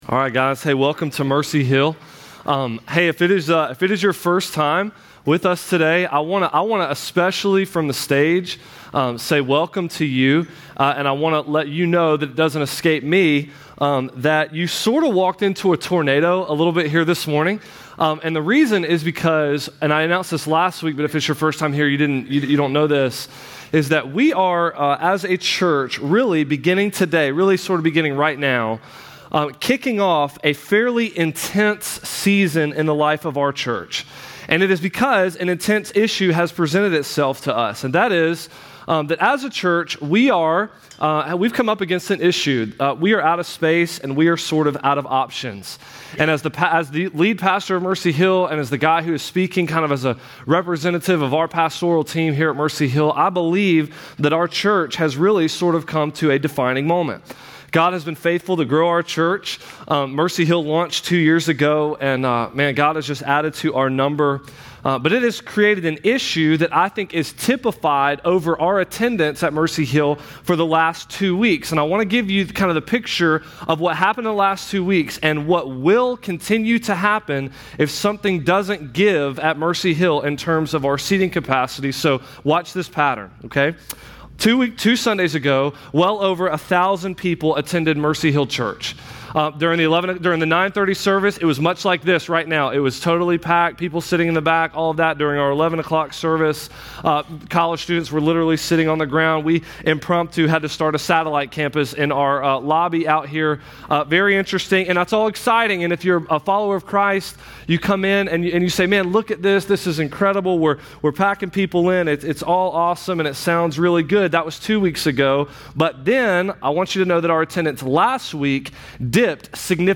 The For His Name sermon series dives into what it means to do great things for the gospel.